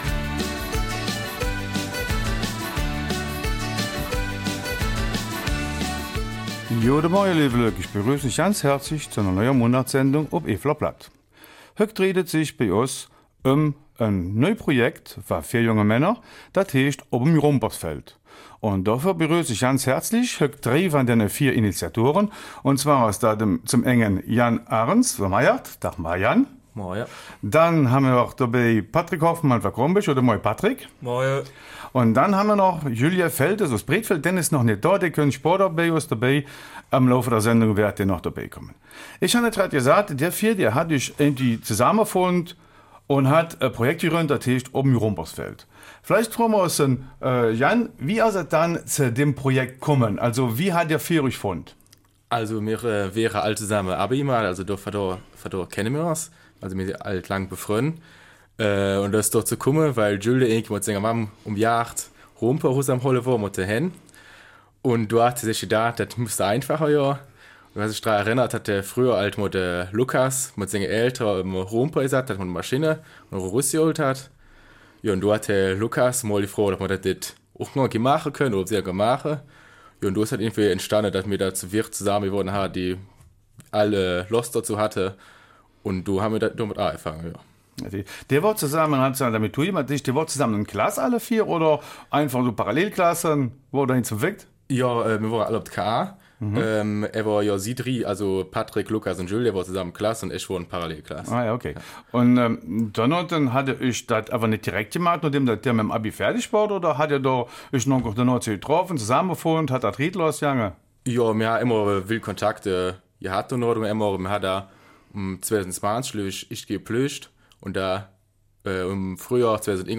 Eifeler Mundart - 3. September